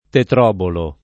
tetrobolo [ tetr 0 bolo ]